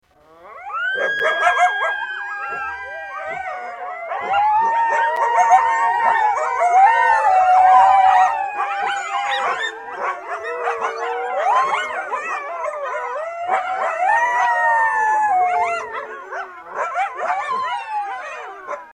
Стая диких койотов воет и рычит